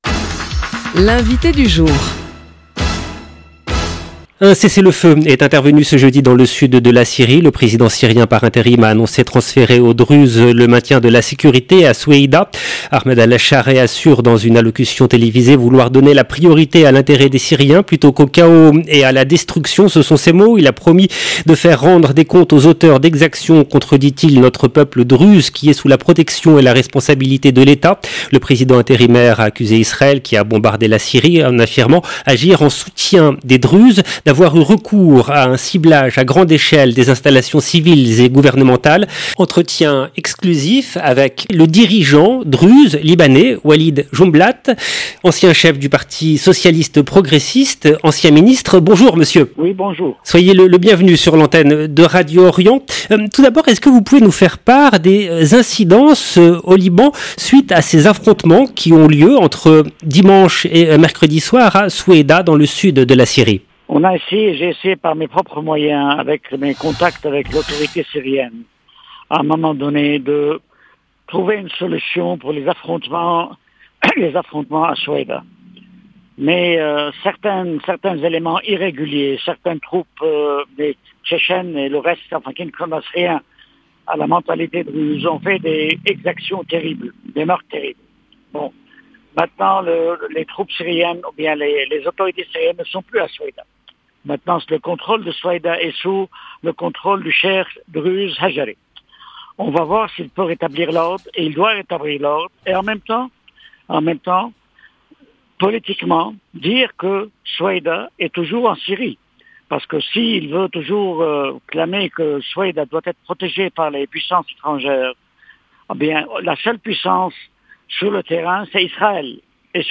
Cessez-le-feu à Soueïda : entretien exclusif avec Walid Joumblatt sur Radio Orient
Entretien exclusif pour Radio Orient avec le dirigeant druze libanais Walid Joumblatt, ancien chef du Parti socialiste progressiste, ancien ministre. 0:00 7 min 27 sec